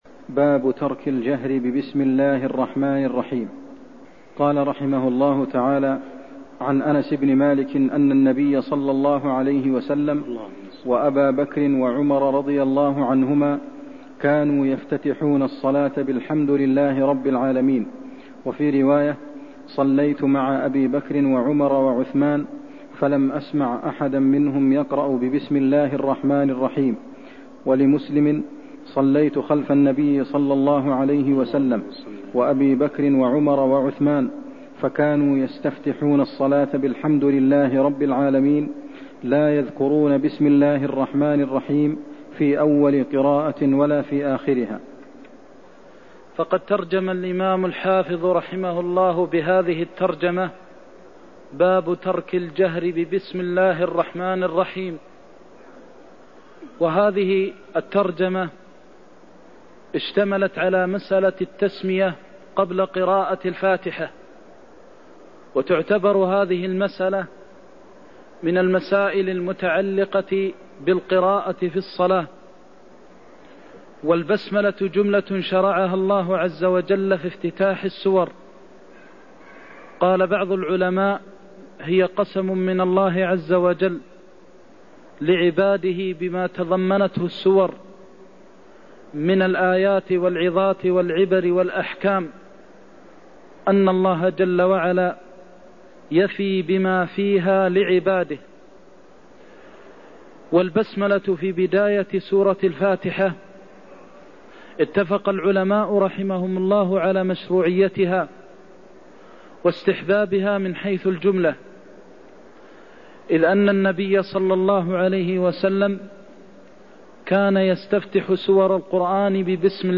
المكان: المسجد النبوي الشيخ: فضيلة الشيخ د. محمد بن محمد المختار فضيلة الشيخ د. محمد بن محمد المختار كانوا يستفتحون الصلاة بالحمد لله رب العالمين (99)